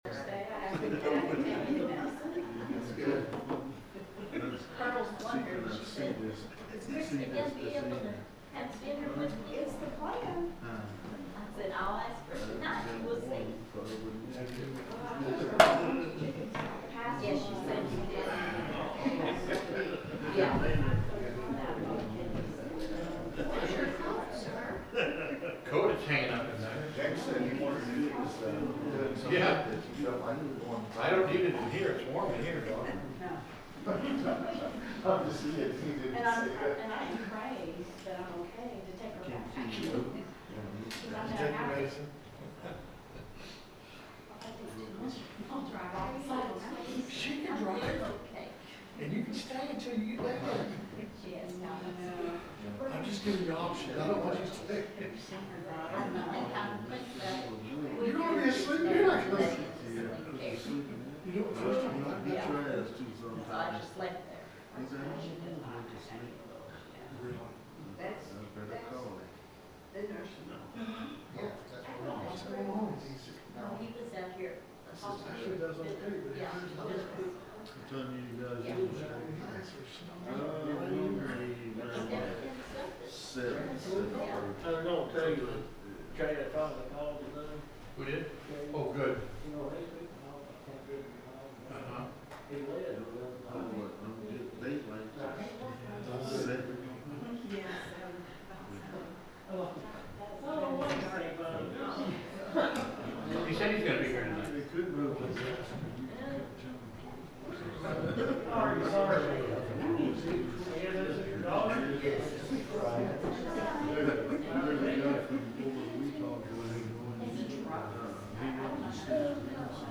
The sermon is from our live stream on 11/19/2025